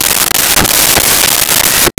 Saw Wood 04
Saw Wood 04.wav